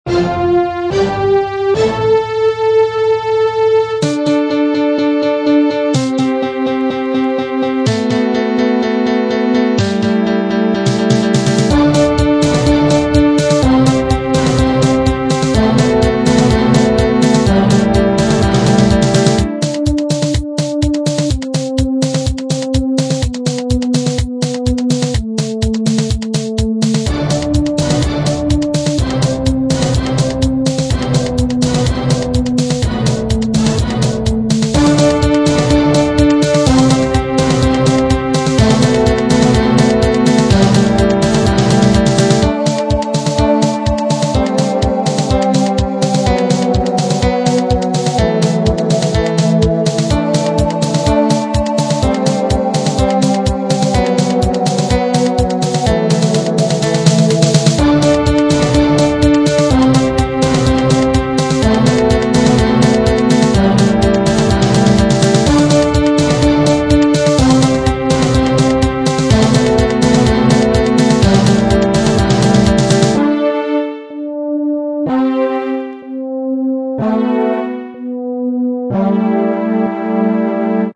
Вот тоже полностью по нотам смастерил
нормадьно, хотя и немного примитивно